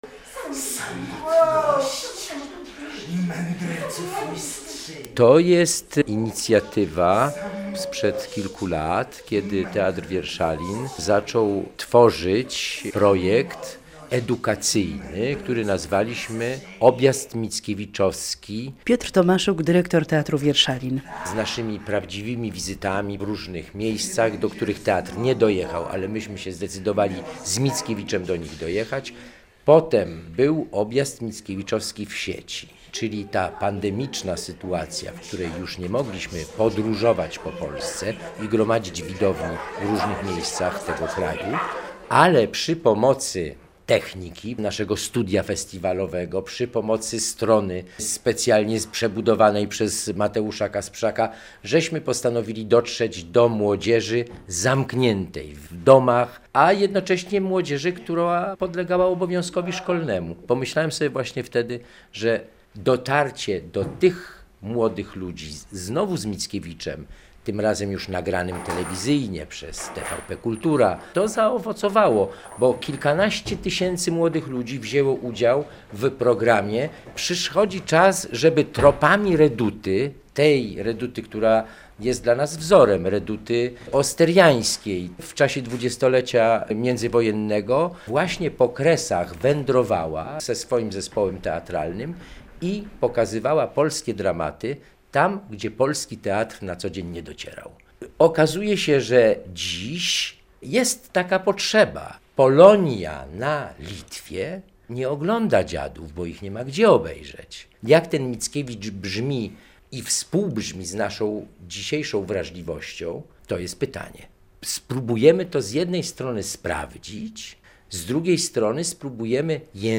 Teatr Wierszalin realizuje projekt Objazd Mickiewiczowski - relacja